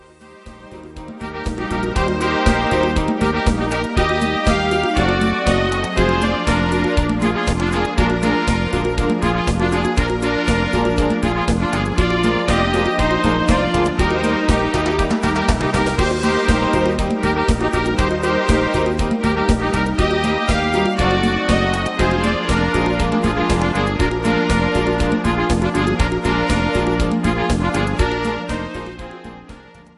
instrumental Orchester